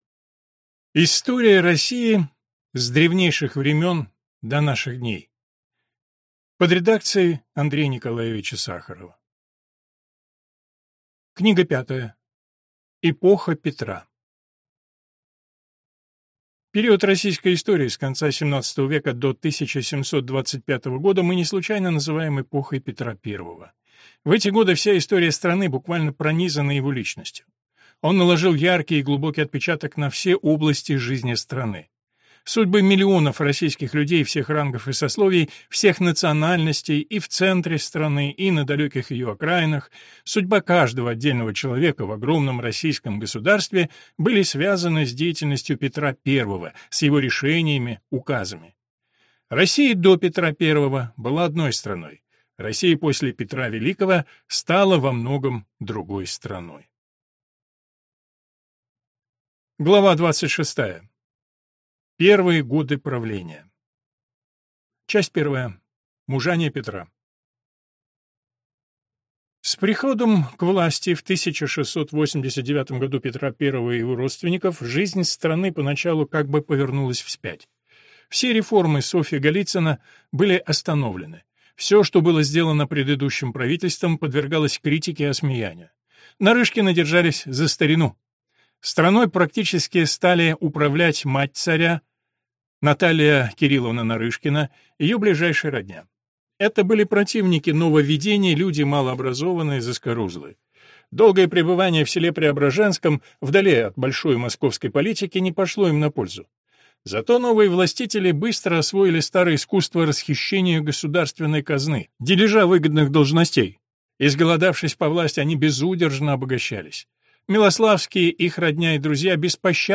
Аудиокнига История России с древнейших времен до наших дней. Книга 5. Эпоха Петра | Библиотека аудиокниг